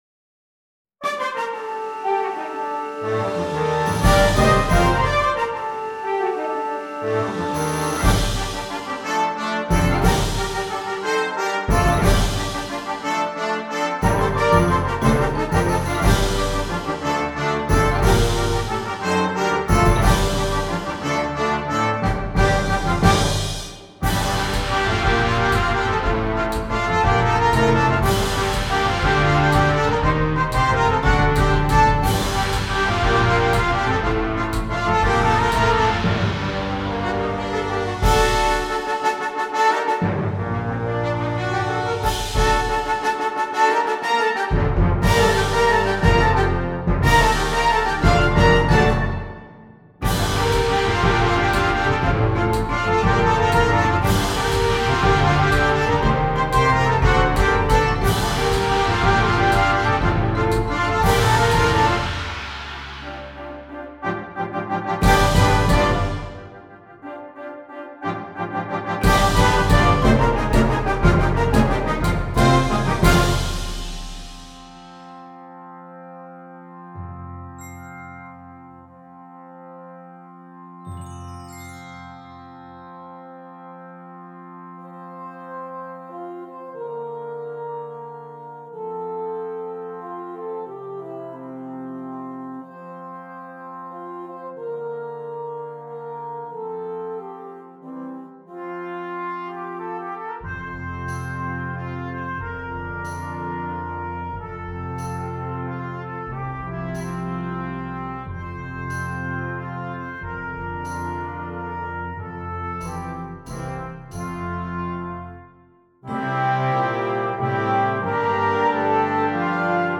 Voicing: Brass Choir